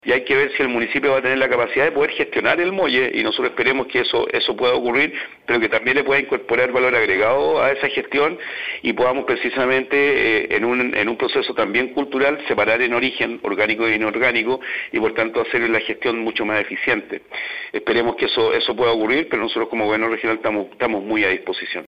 Por lo que, la autoridad explicó esta propuesta y destacó la importancia de la cultura en este proceso.
cu-vertedero-gobernador-regional.mp3